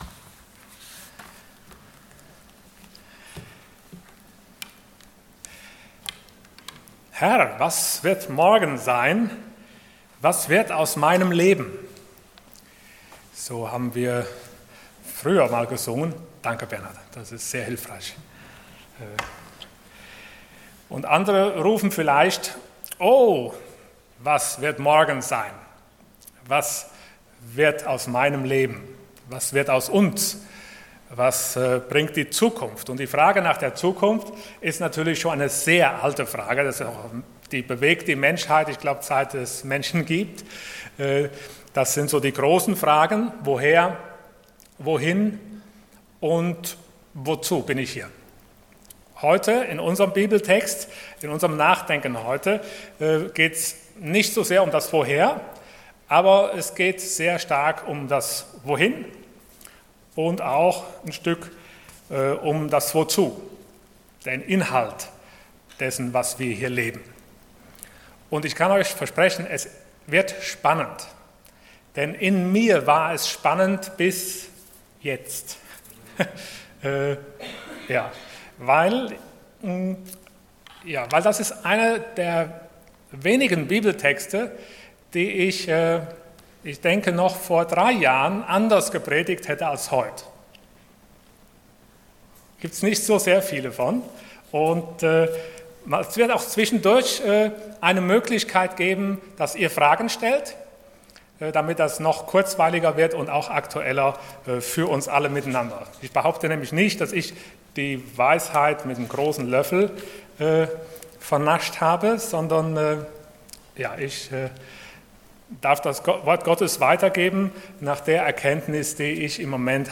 Passage: 1 Thessalonians 4:13-18 Dienstart: Sonntag Morgen